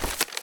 Map Open.wav